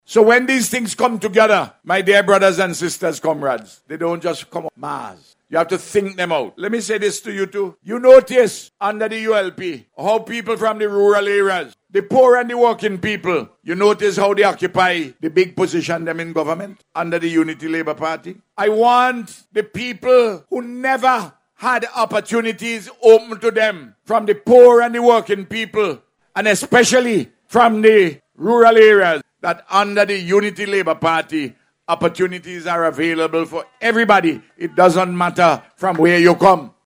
Prime Minister Dr. Ralph Gonsalves made this statement during the ceremony which was held on Tuesday to distribute title deeds to more than eighty residents of North Windward.